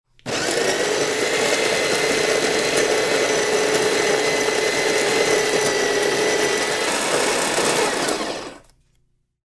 Звук перемалывания обжаренных кофейных зерен